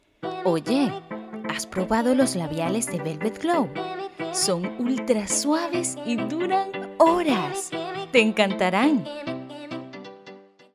Joven, Natural, Amable, Cálida, Suave
Comercial